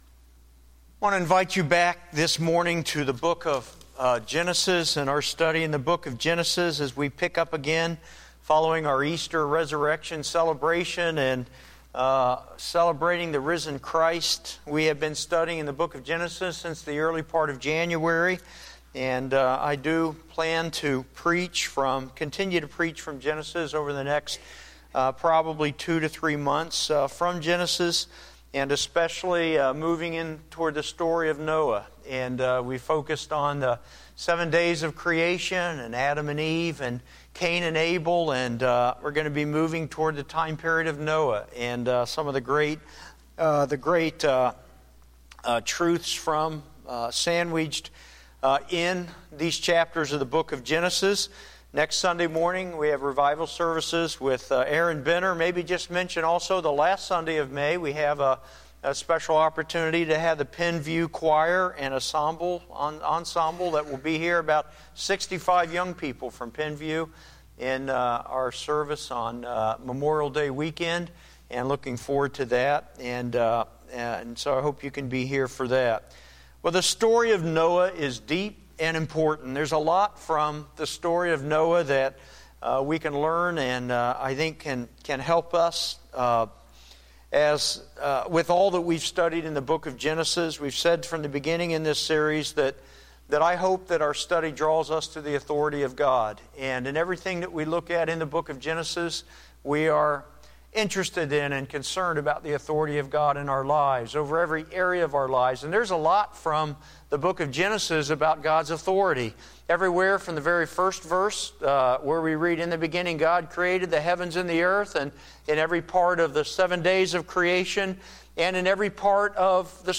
4-27-14-9am-Sermon.mp3